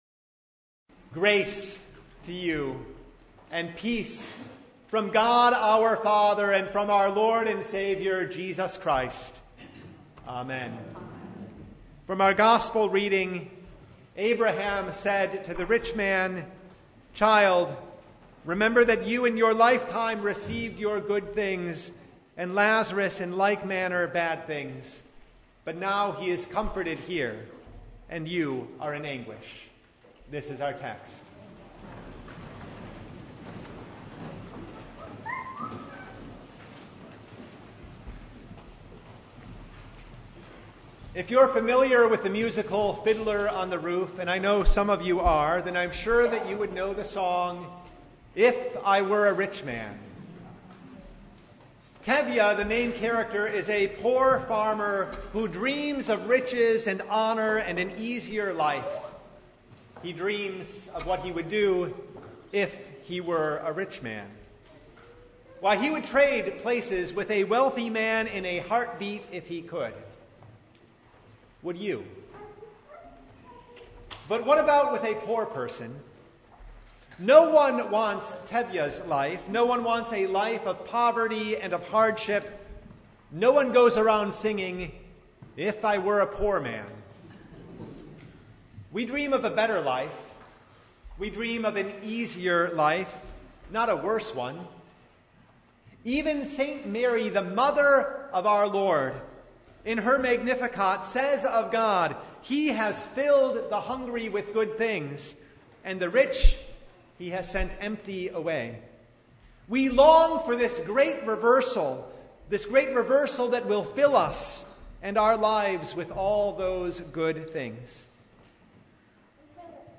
Luke 16:19-31 Service Type: Sunday Would you rather trade places with the rich man or with poor Lazarus who had nothing in this life?